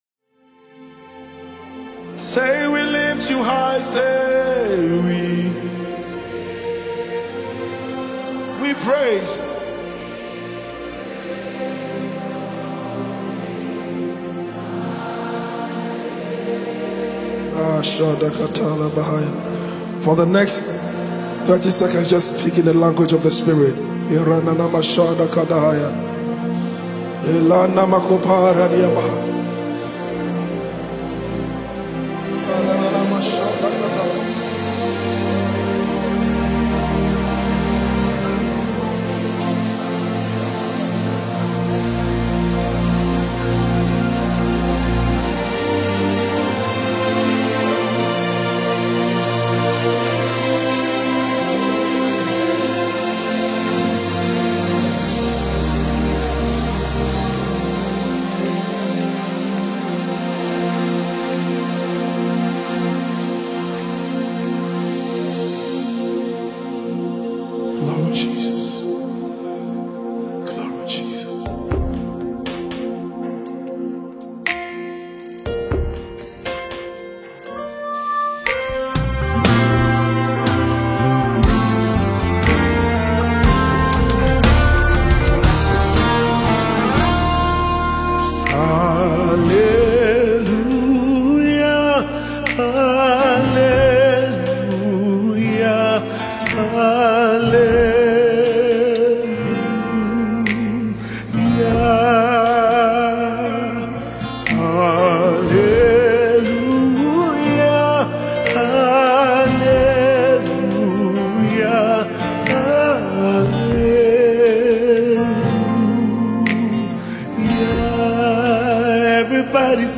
January 17, 2025 Publisher 01 Gospel 0